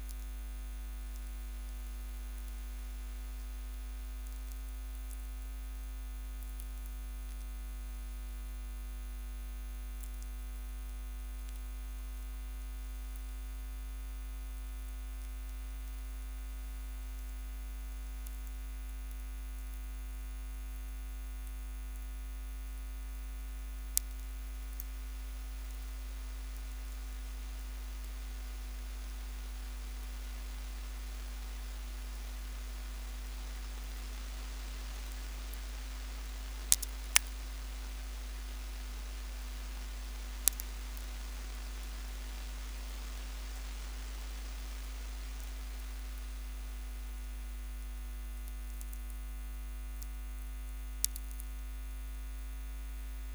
Durante la notte compare spesso un rumore a banda larga che si estende da 0 a circa 10 kHz; dura qualche decina di secondi e poi scompare, a volte comparendo nuovamente poco dopo. Ad orecchio suona come un'onda del mare che arriva sulla spiaggia o come un colpo di vento. L'origine è probabilmente naturale, ma non saprei identificarla.
Rumore "marino"